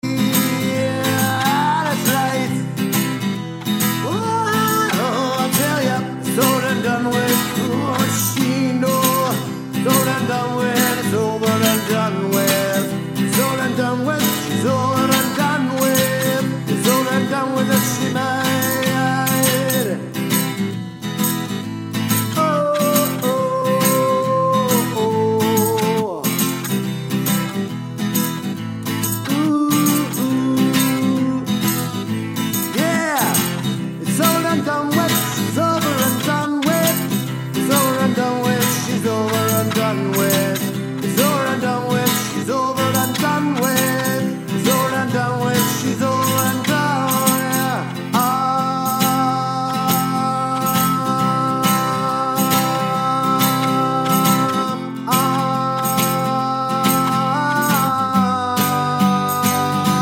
For Solo Singer Pop (1980s) 2:42 Buy £1.50